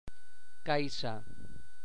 Observem a continuació la representació espectrogràfica de caixa amb realització despalatalitzada de /S/.
[káisa].